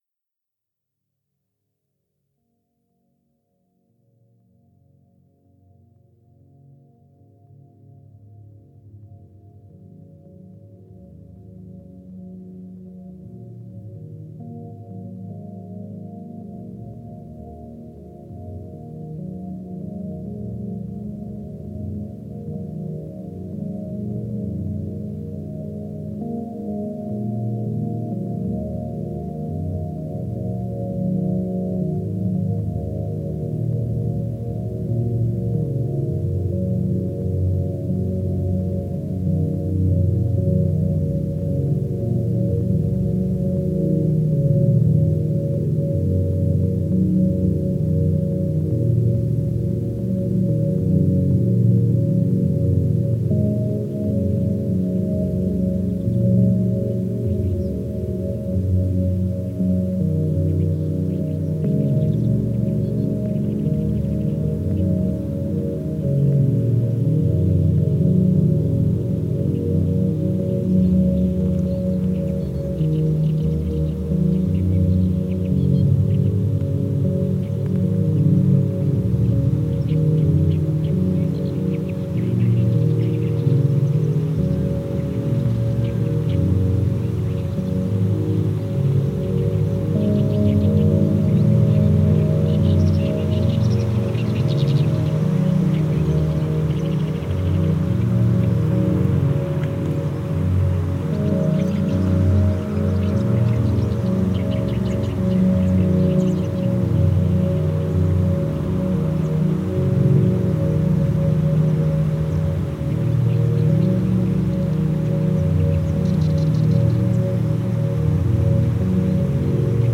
Genre: Ambient/Field Recording.